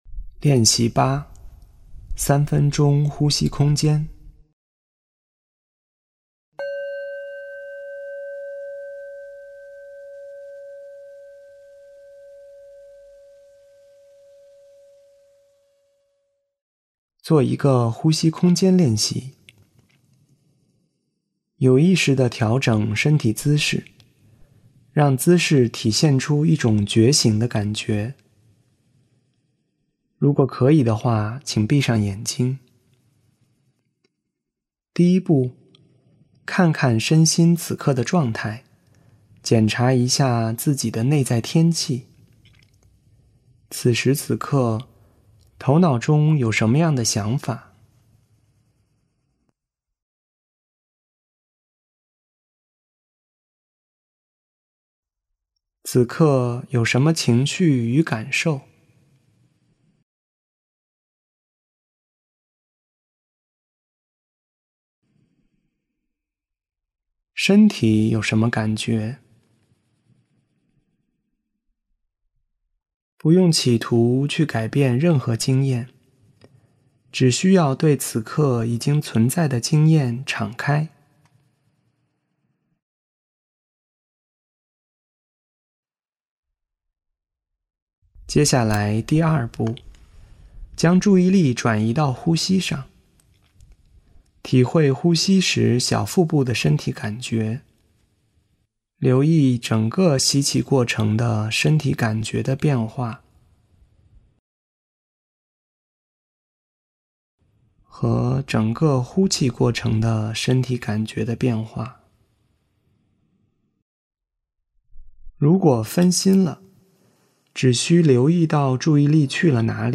3分钟呼吸空间练习
3分钟呼吸空间.mp3